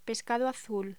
Locución: Pescado azul
voz